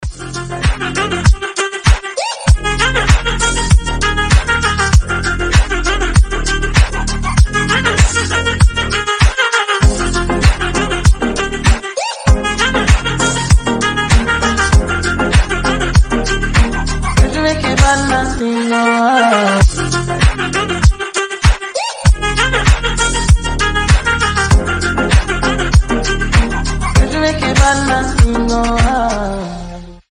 • Качество: 320, Stereo
Moombahton
ремиксы